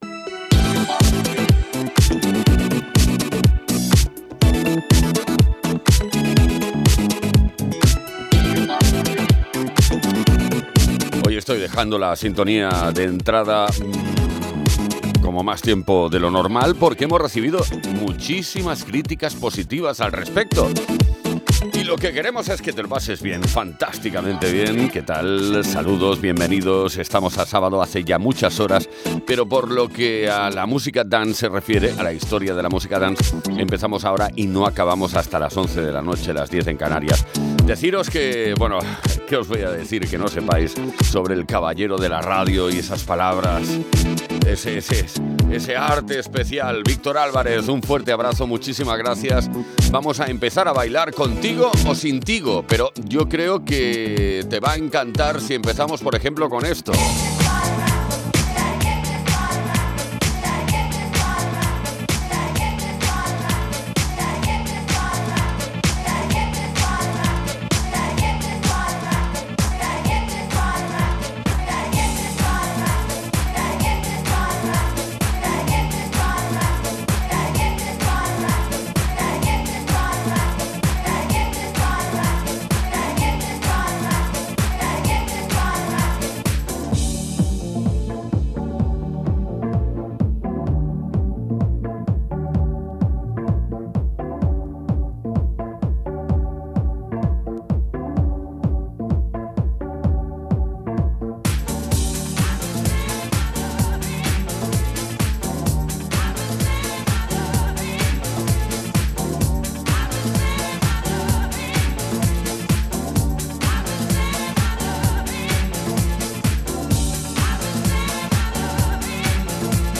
Lo mejor de los 80 y los 90 hasta hoy